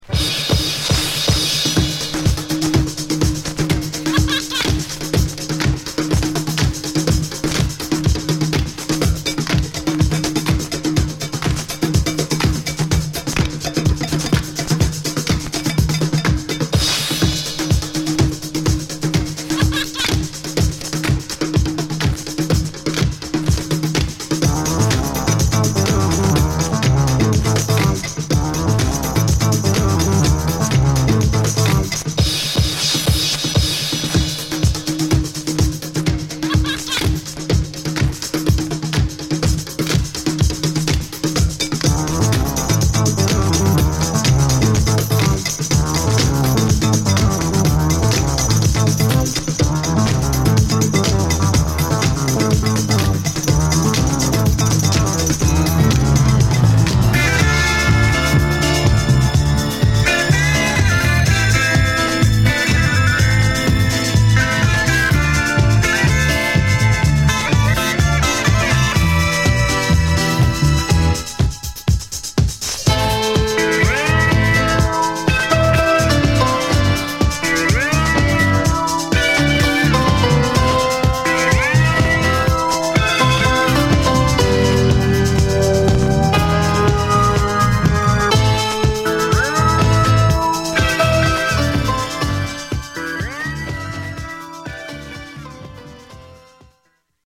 *中盤薄っすらノイズ箇所有り。